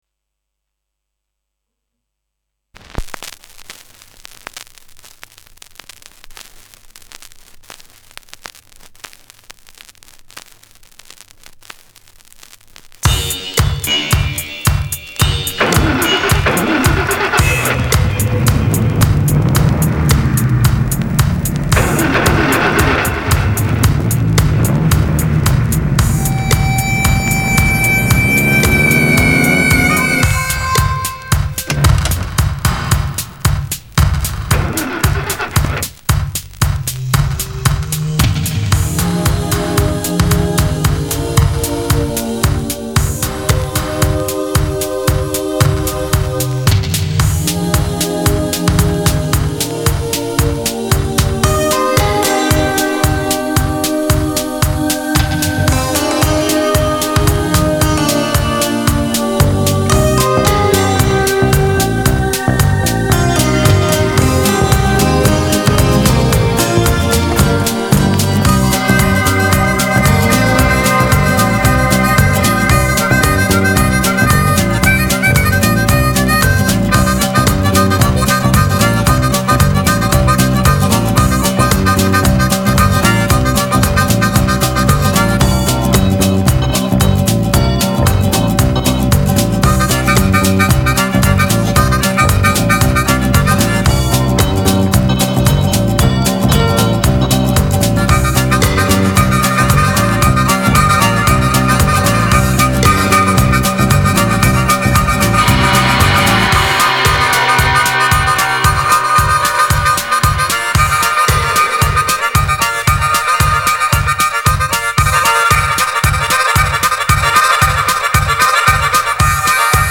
vinyl crackles ‘n all;